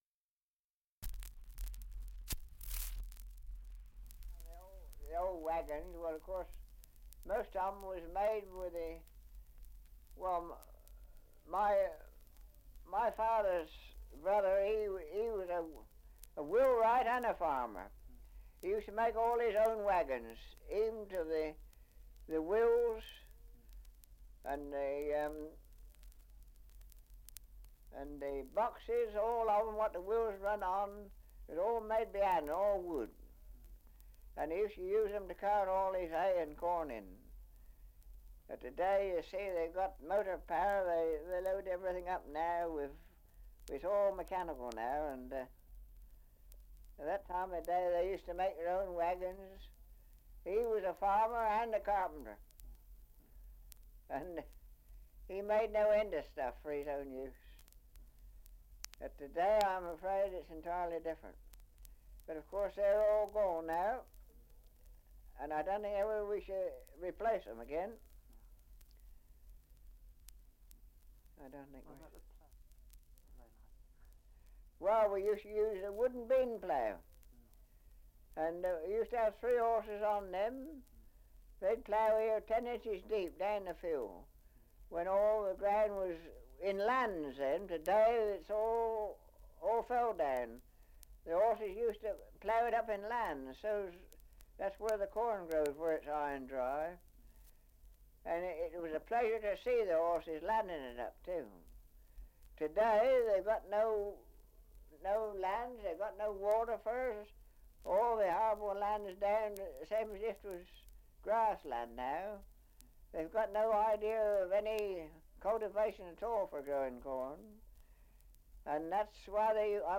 Survey of English Dialects recording in Outwood, Surrey
78 r.p.m., cellulose nitrate on aluminium